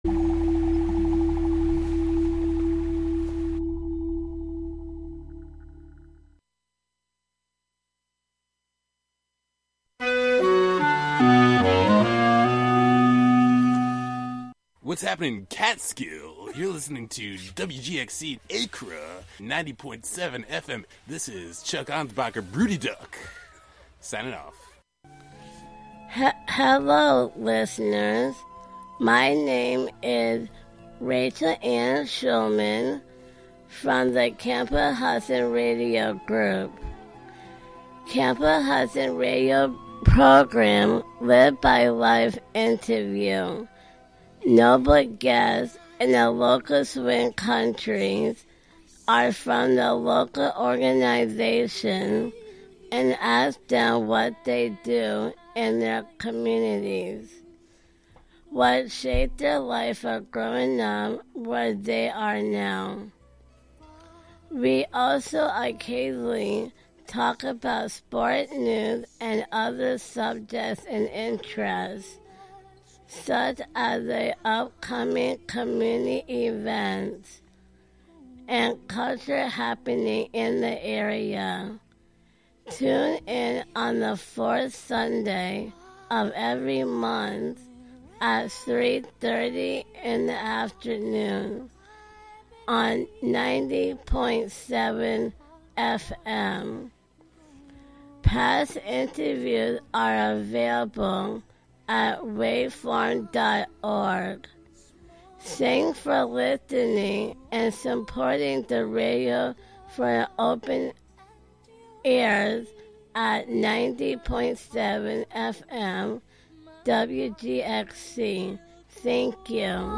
broadcasting live from the front window of the WGXC Catskill Studio at 393 Main St. Catskill, NY. Local event listings, in-studio / pedestrian interviews, call-ins, live music, and many other cords of connection will be aired.